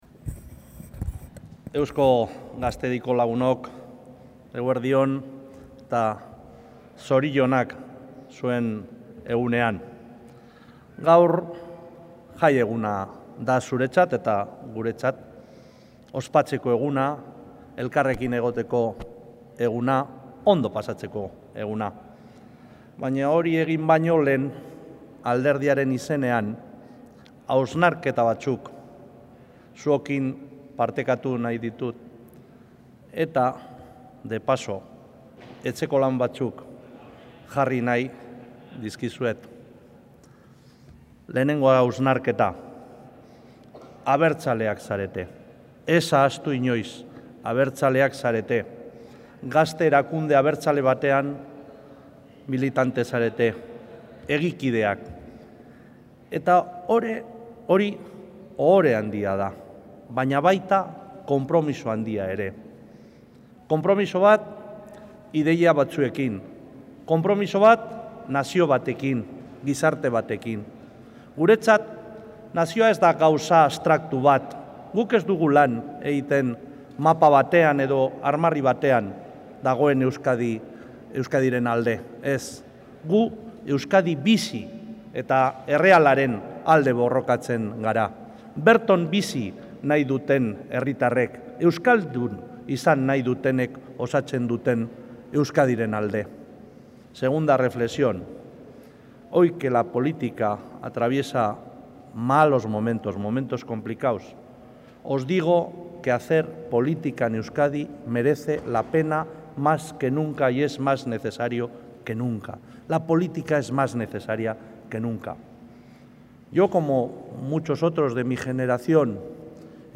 Este ha sido el principal mensaje que el presidente del Euzkadi Buru Batzar, Andoni Ortuzar, ha querido lanzar al medio millar de jóvenes que han asistido en Lesaka al acto político del EGI Eguna, la fiesta de las juventudes de EAJ-PNV, que este año se ha celebrado en esta localidad navarra. Un encuentro en el que también han intervenido la portavoz del Gobierno de Nafarroa, María Solana, y el parlamentario de Geroa Bai Jokin Castiella.